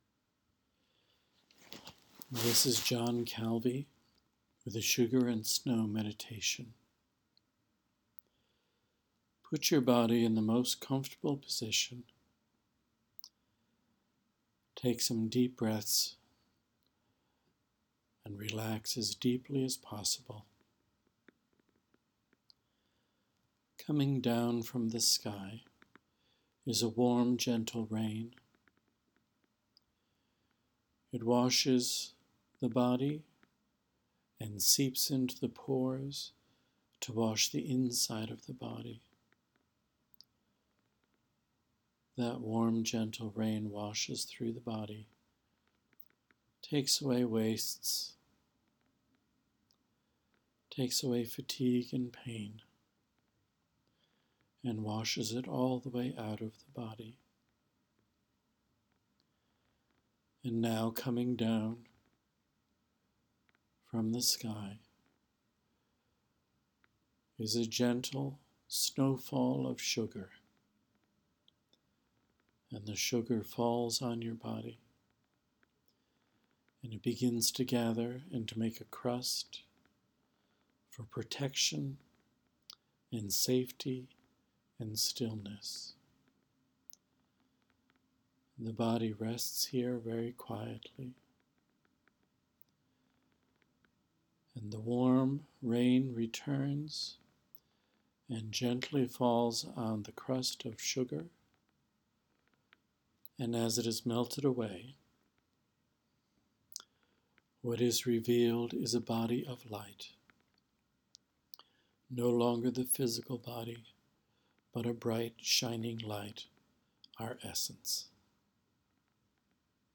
Sugar & Snow Meditation